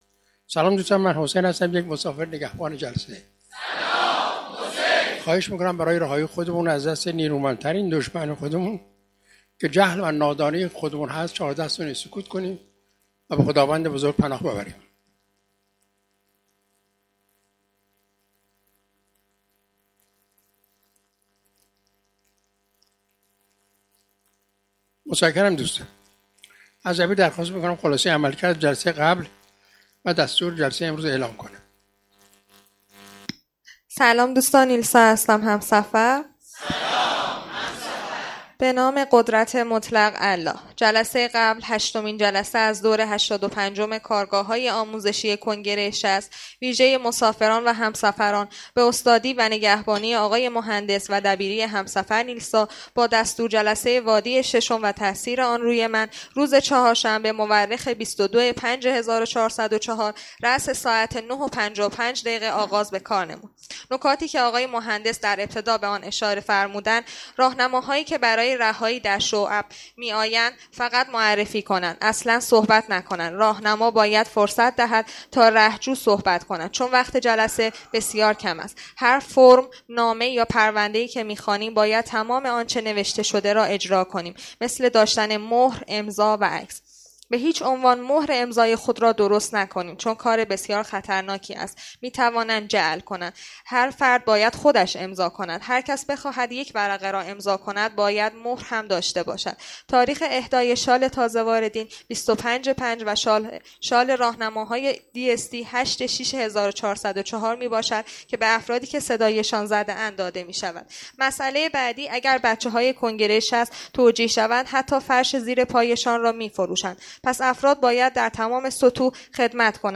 کارگاه آموزشی جهان‌بینی؛ دانایی، دانایی موثر و سواد